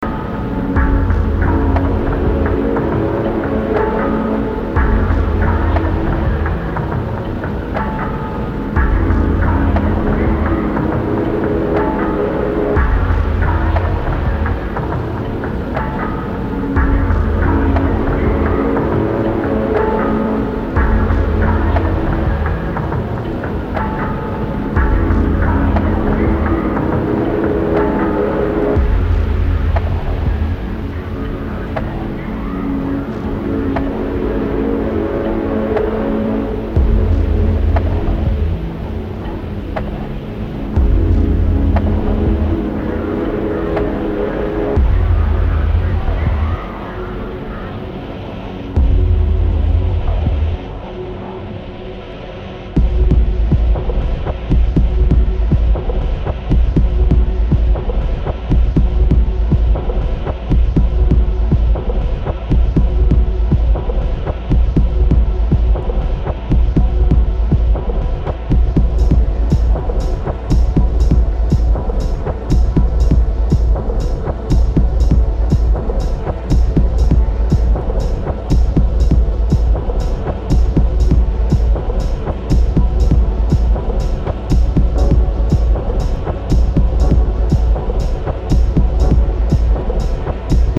an exploration of angular, brittle club music.
Electronix Techno